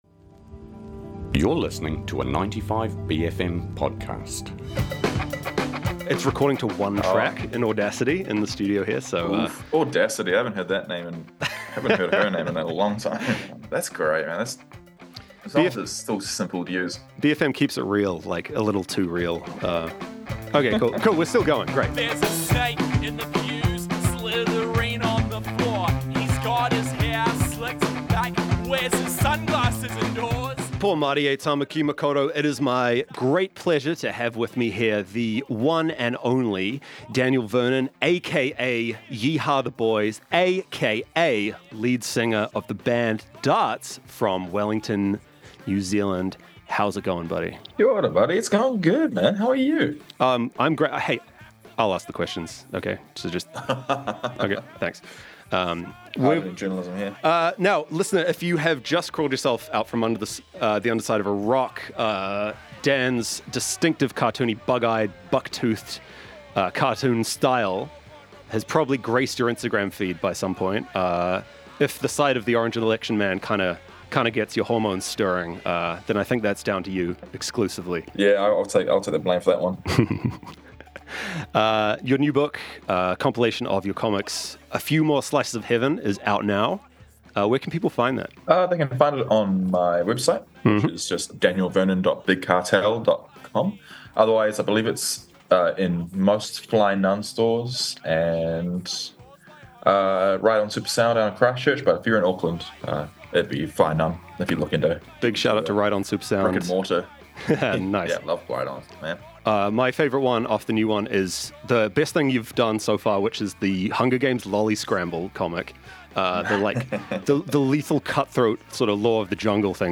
Guest Interview w